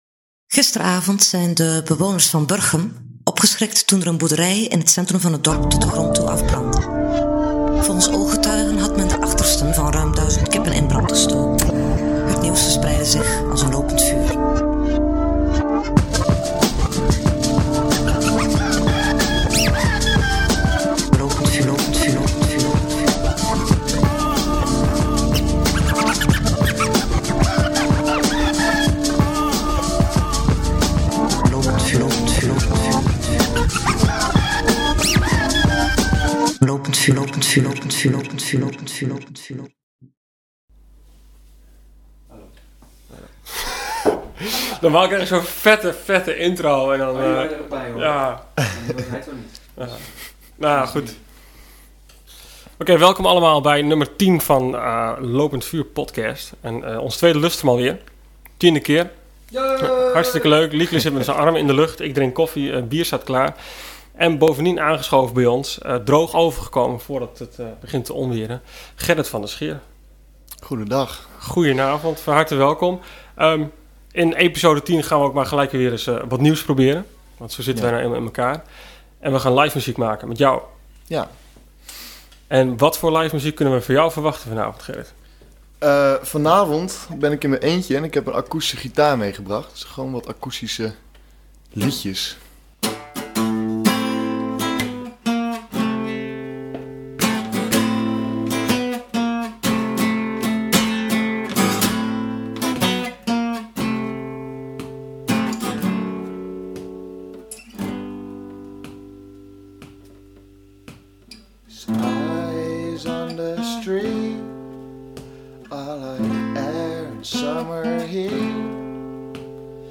In deze tiende (lustrum)uitzending gaan we voor het eerst live!